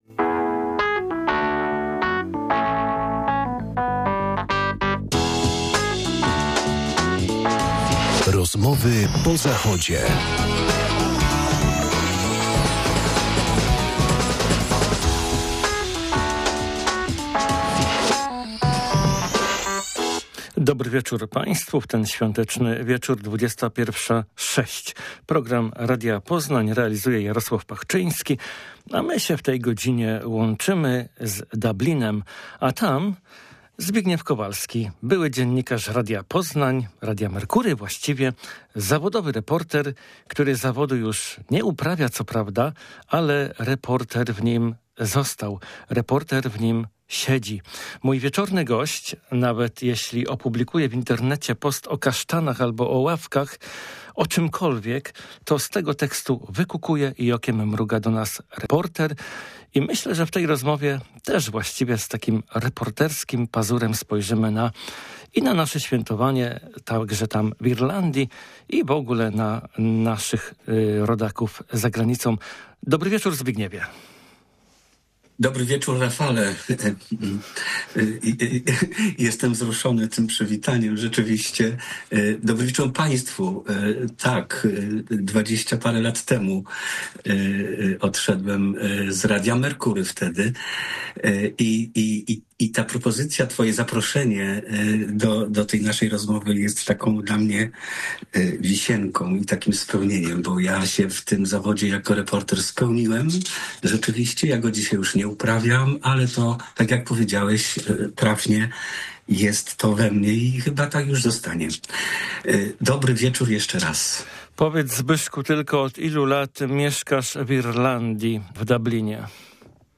Jak spędził polskie święto? Czy Polacy obchodzili ten dzień, no i w ogóle: jak wygląda patriotyzm emigranta? To tylko niektóre kwestie poruszone w wieczornej rozmowie.